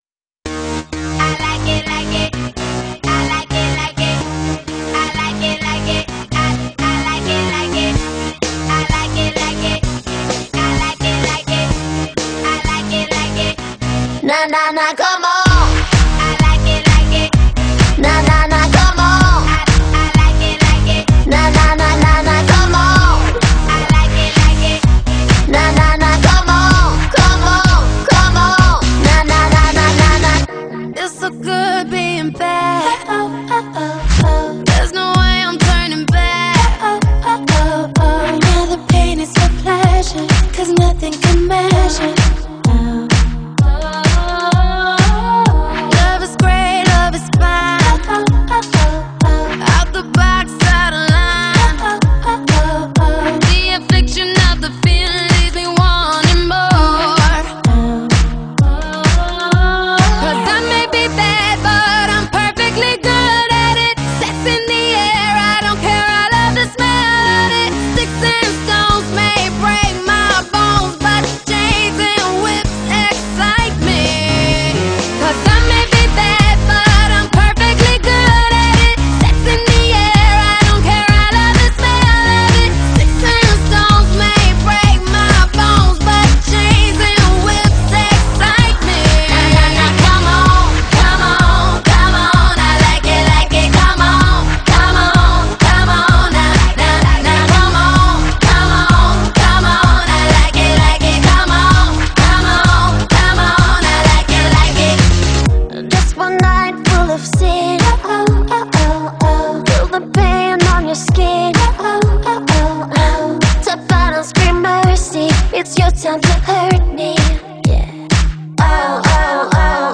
duetto